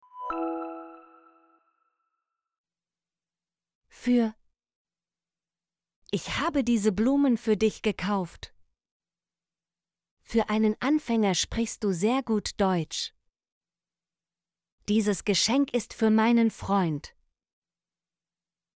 für - Example Sentences & Pronunciation, German Frequency List